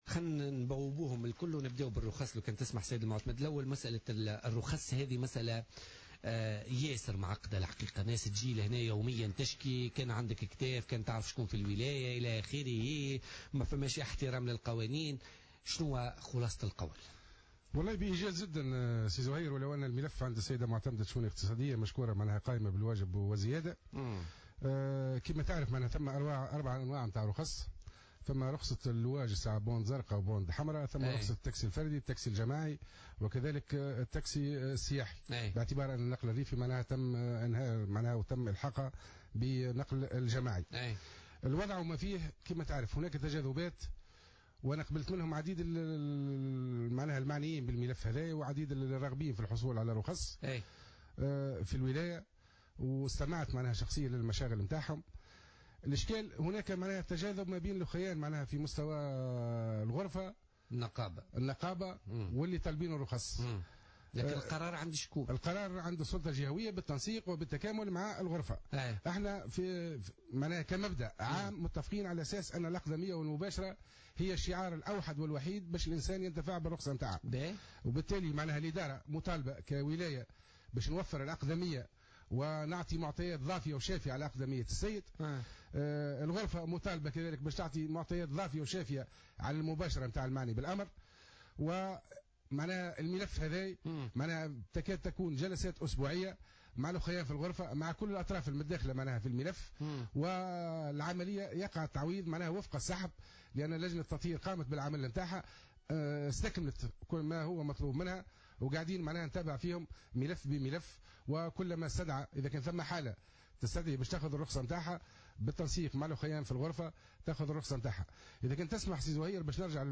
قال المعتمد الأول لولاية سوسة حسين بوشهوة خلال استضافته اليوم في "بوليتكا"، إن ميزانية ولاية سوسة لسنة 2017، قدرت ب10 مليون دينار، وأعلن عن خطة صرف الميزانية.